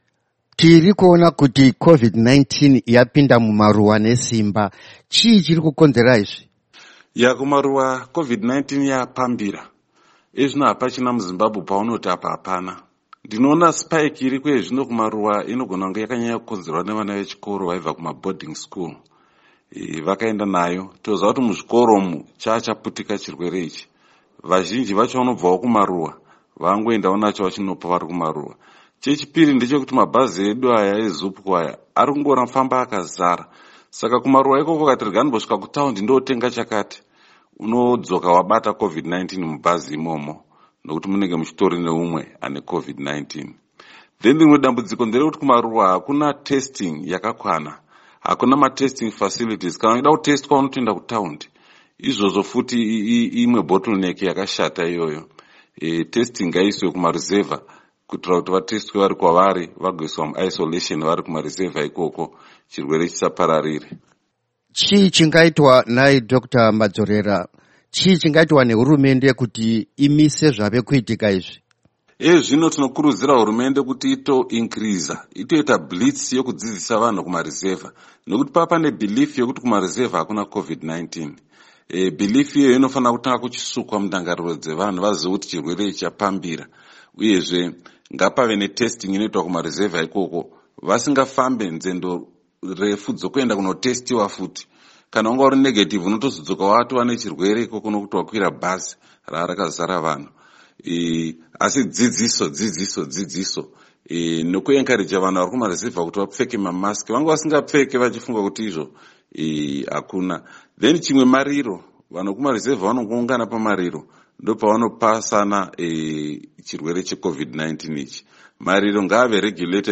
Hurukuro naDr Henry Madzorera